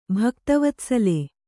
♪ bhakta vatsale